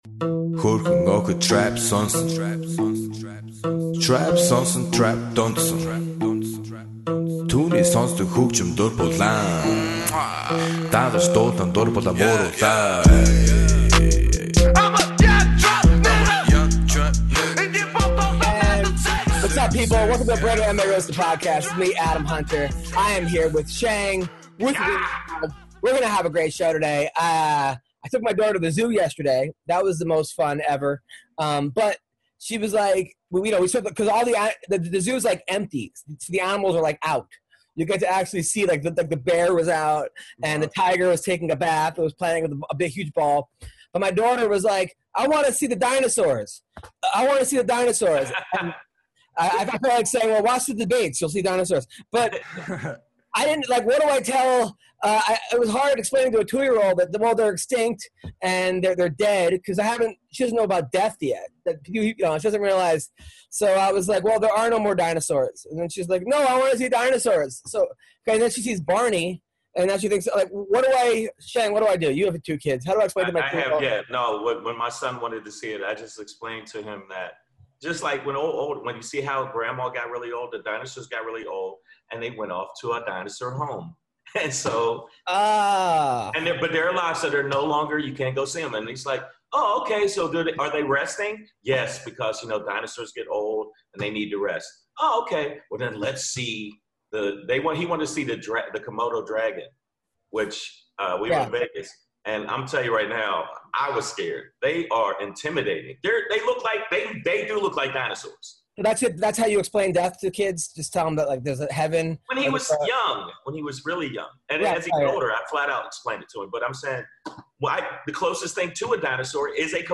On this episode of The MMA Roasted Podcast, MMA fighter Juan Archuleta calls in to discuss his journey to becoming the Bellator Bantamweight Champion. The crew talks about trending MMA news and takes a look at this weekend's UFC card, Moraes vs. Sandhagen.